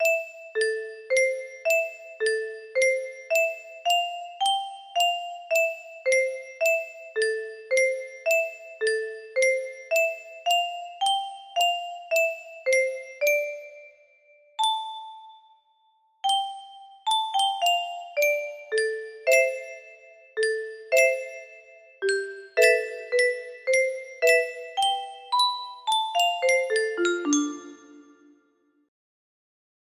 Clone of Unknown Artist - Untitled music box melody